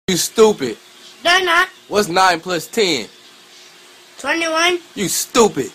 Goofy Ahh Monkey Sound Effect Free Download